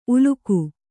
♪ uluku